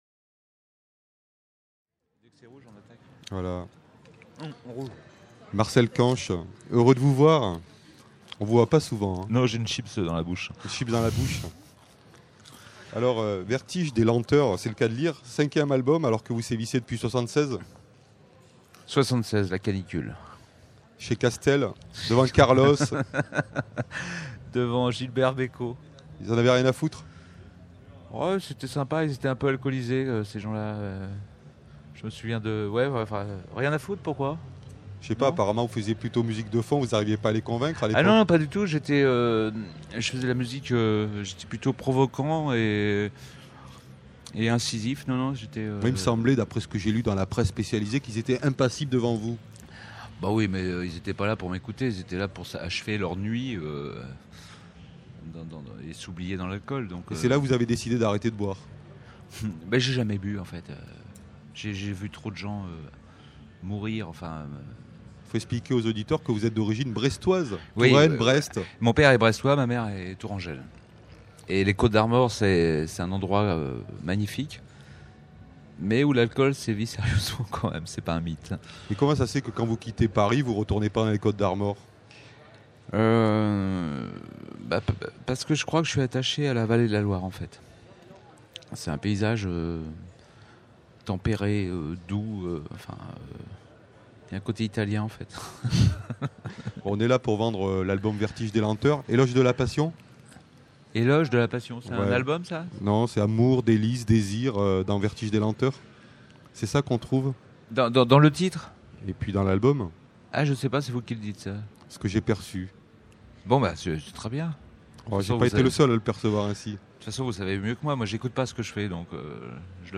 Marcel Kanche Festival du Printemps de Bourges 2006 : 40 Interviews à écouter !